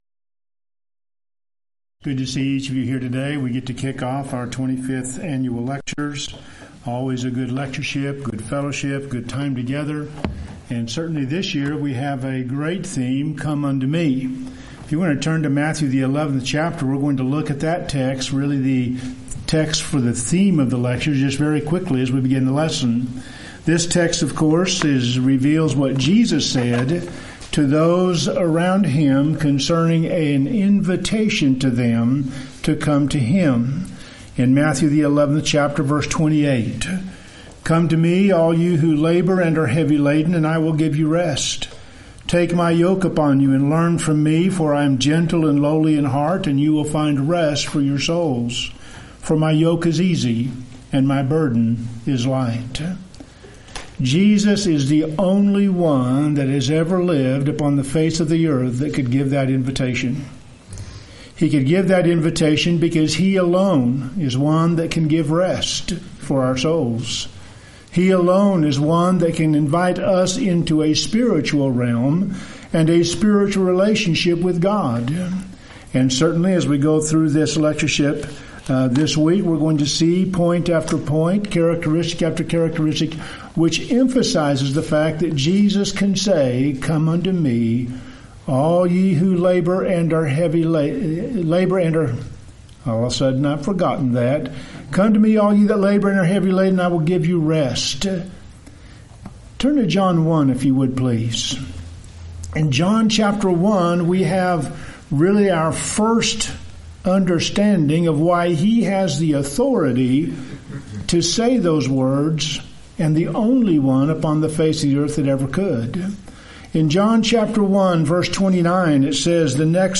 Event: 25th Annual Lubbock Lectures
lecture